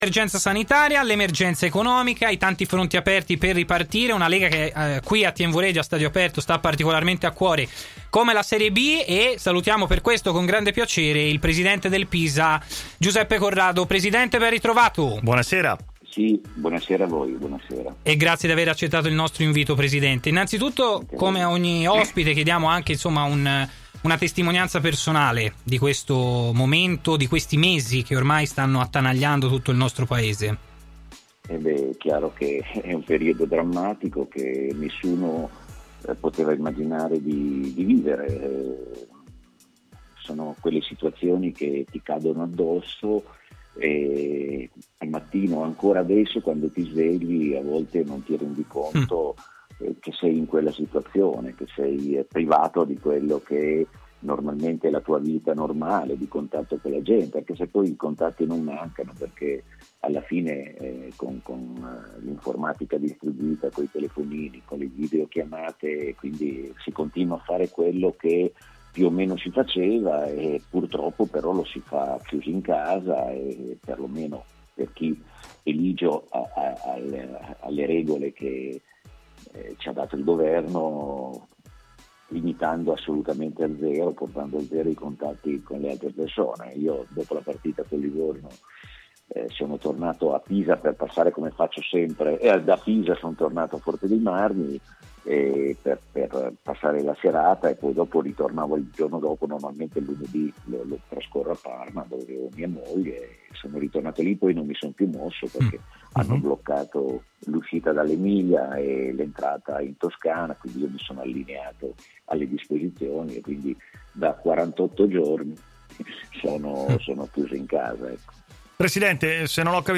si è collegato in diretta con TMW Radio nel corso della trasmissione Stadio Aperto